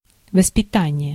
Ääntäminen
IPA: [ɑ̃.sɛ.ɲə.mɑ̃]